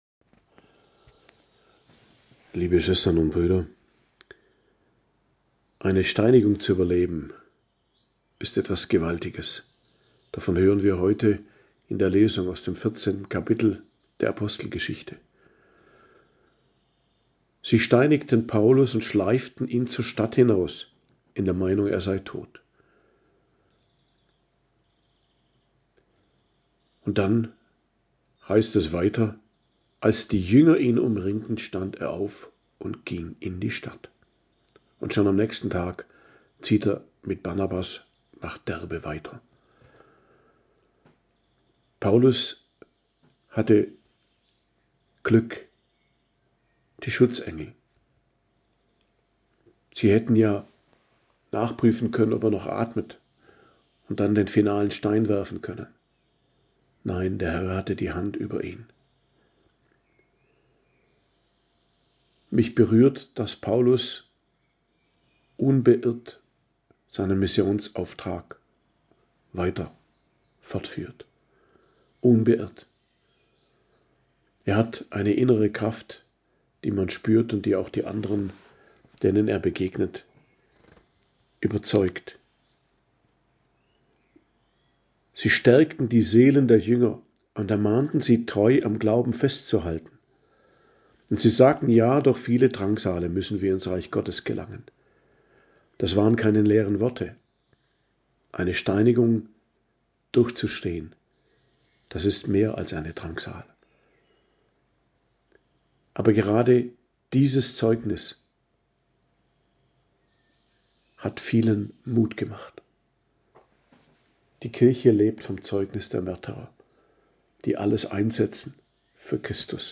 Predigt am Dienstag der 5. Osterwoche, 20.05.2025